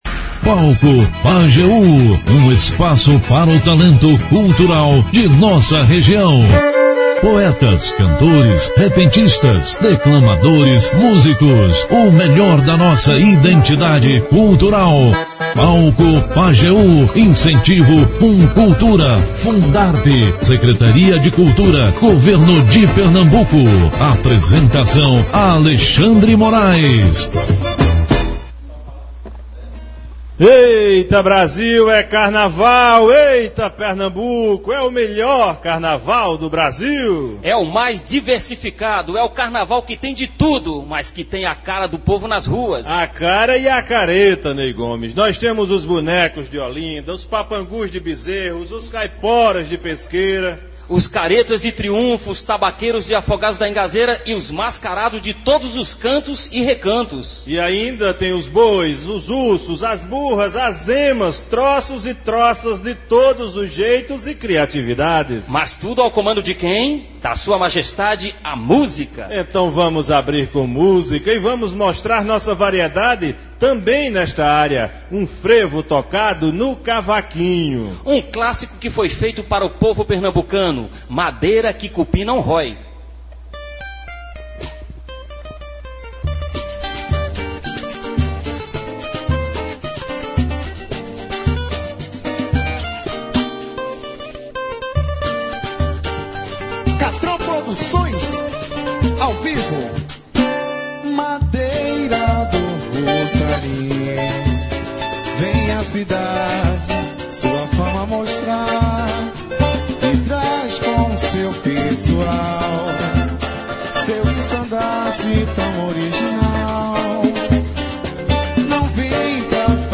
Aconteceu fora dos estúdios da Rádio Pajeú. O programa foi para o foco da folia, na Leve Pizza, que fica na Avenida Rio Branco, onde aconteceu a abertura oficial do carnaval 2020 de Afogados da Ingazeira.
A orquestra Show de Frevo chegou de surpresa e o carnaval foi grande.